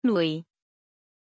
Pronunciation of Lewie
pronunciation-lewie-zh.mp3